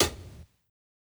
Closed Hats
HIHAT_FUNERAL.wav